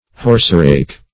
Horserake \Horse"rake`\, n. A rake drawn by a horse.